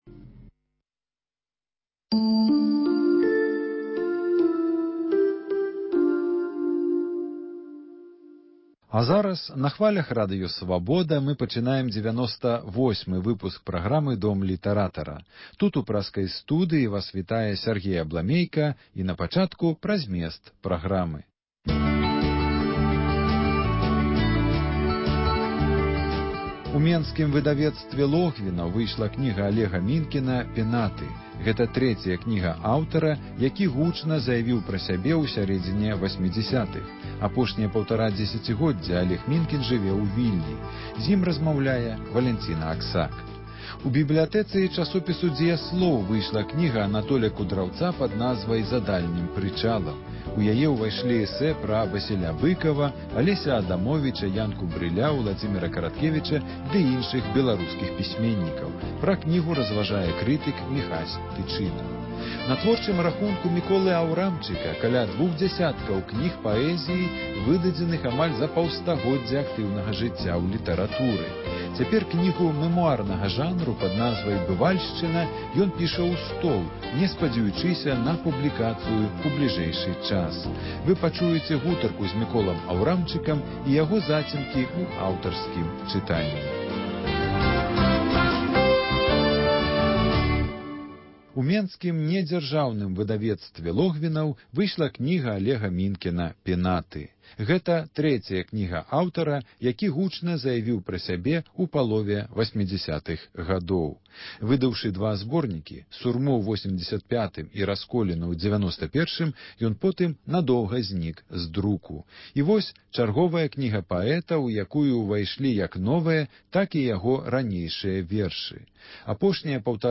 Літаратурны агляд: інтэрвію